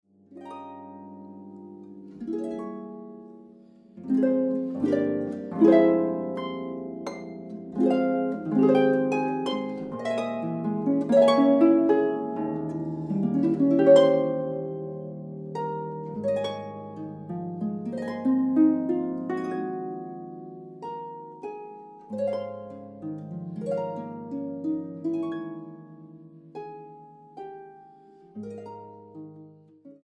Boonkker Audio Tacubaya, Ciudad de México.